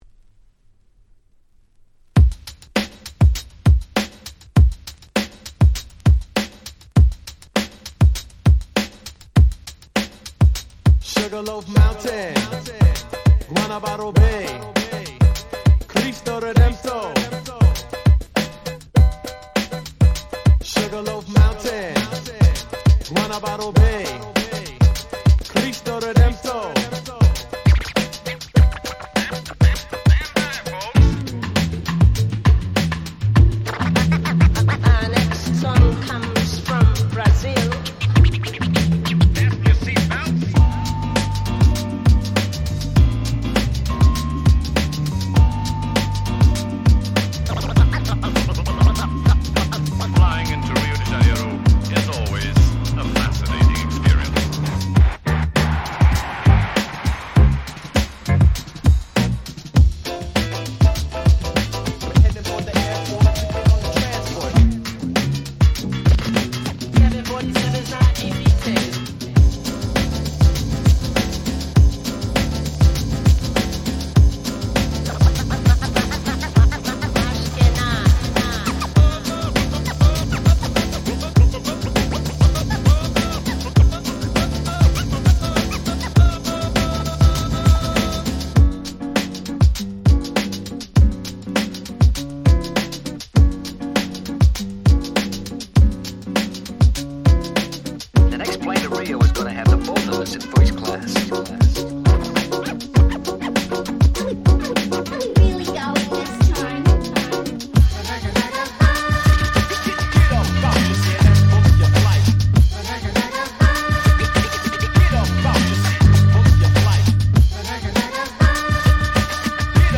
Boom Bap